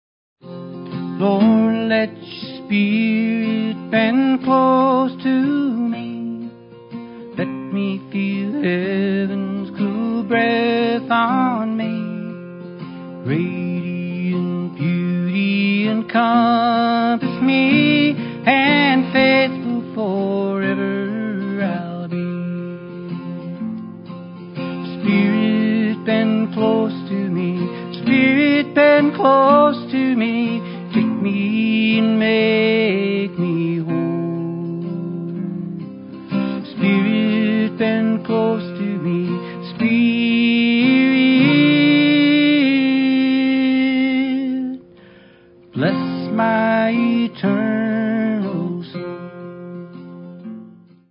recorded live in front of an audience